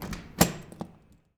DOOR OP A -S.WAV